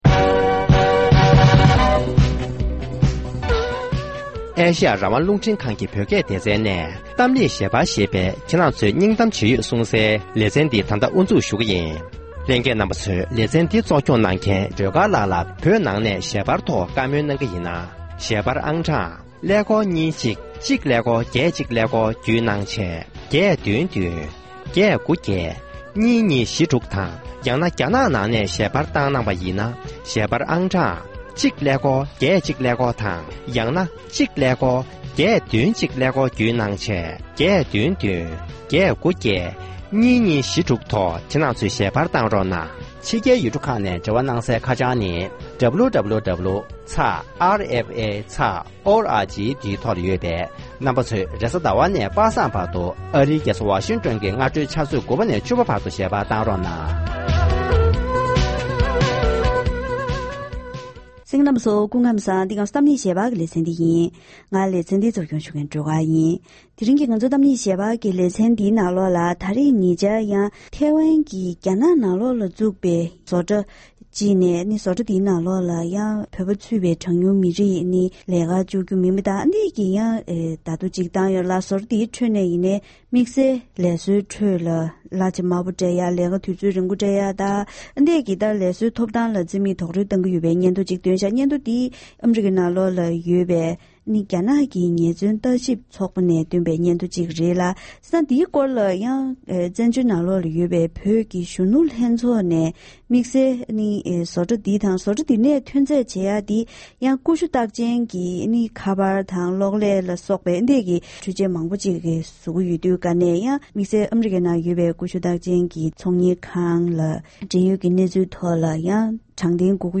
༄༅༎དེ་རིང་གི་གཏམ་གླེང་ཞལ་པར་ལེ་ཚན་ནང་ཉེ་ཆར་རྒྱ་ནག་ནང་ཡོད་པའི་ཀུ་ཤུ་རྟགས་ཅན་ནམ་ཨེ་པལ་གྱི་མ་ལག་བཟོ་གྲ་ཞིག་གིས་ལས་བཟོ་བསྡུ་བའི་བརྡ་ཁྱབ་ནང་མི་རིགས་དབྱེ་འབྱེད་བྱས་པས་བཙན་བྱོལ་ནང་ཡོད་པའི་བོད་མིས་བོད་མིའི་ཐོབ་ཐང་རྩོད་པའི་ལས་འགུལ་ཞིག་སྤེལ་བ་འདིའི་ཁྲོད་ནས་བོད་ནང་གི་བོད་མིའི་གནས་སྟངས་སྐོར་གླེང་བ་ཞིག་གསན་རོགས་གནང་།།